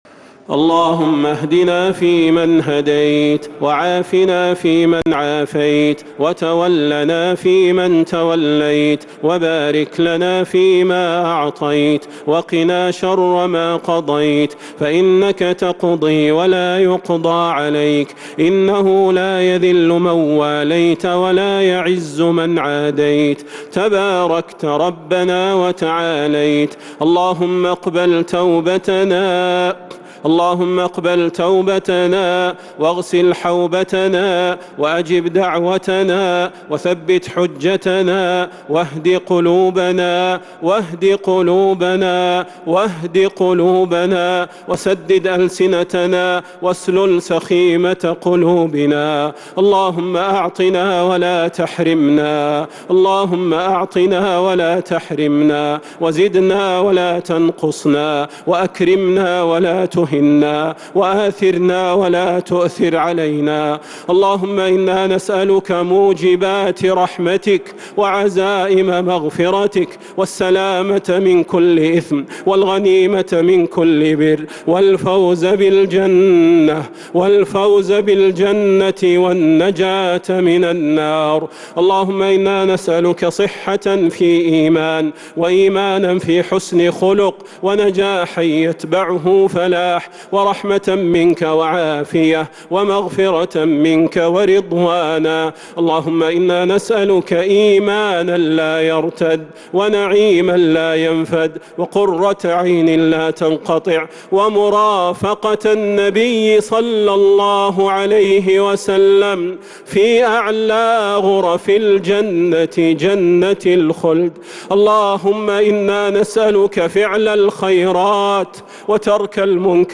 دعاء القنوت ليلة 1 رمضان 1443هـ Dua 1st night Ramadan 1443H > تراويح الحرم النبوي عام 1443 🕌 > التراويح - تلاوات الحرمين